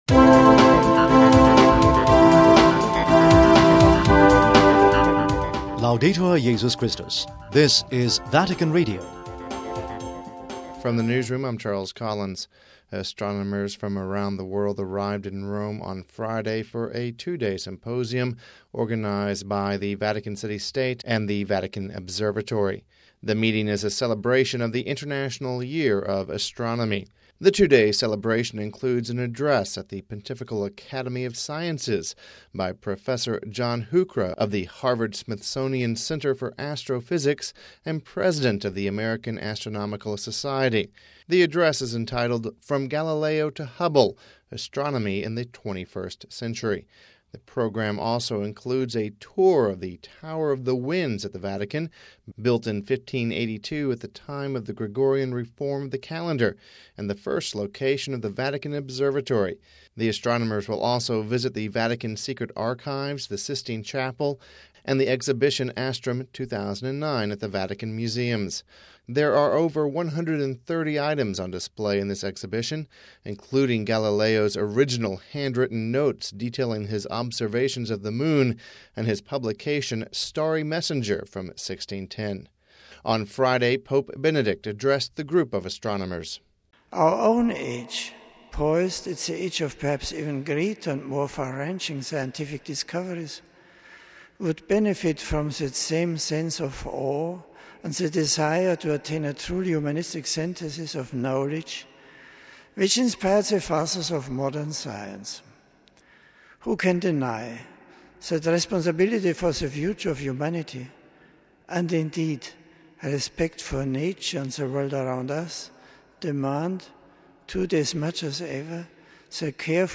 The highlight of the event was a meeting on Friday with Pope Benedict XVI. We have this report...